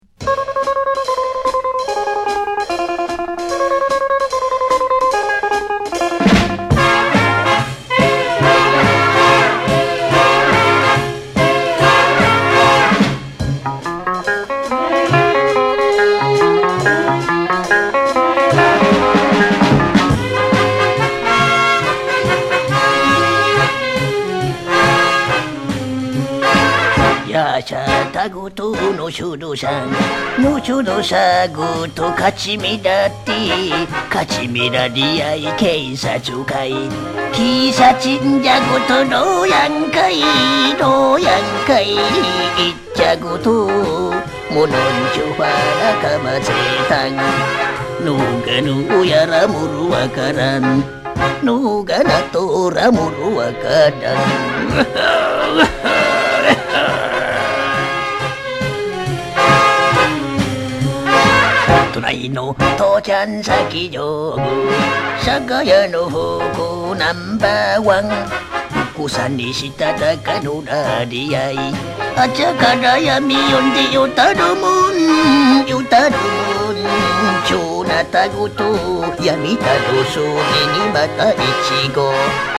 沖縄伝統音楽にロックやソウルのグルーヴを施した人気コンピレーション